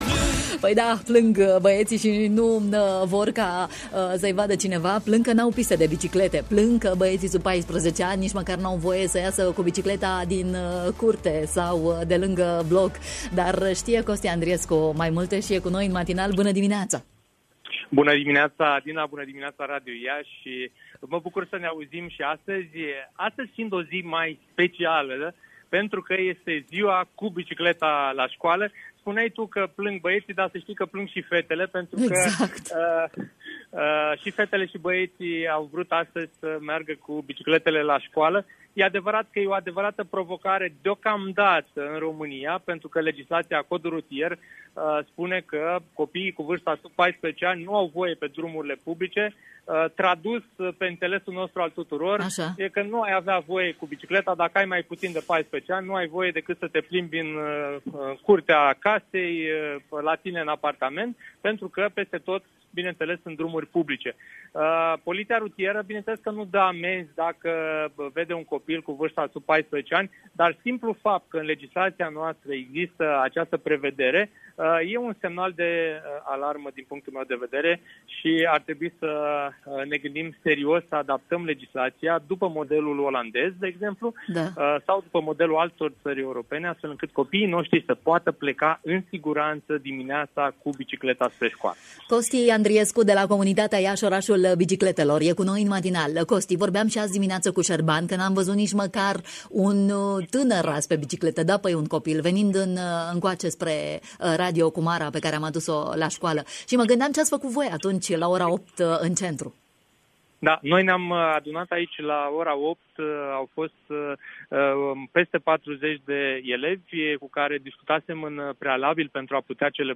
în direct la Radio România Iaşi: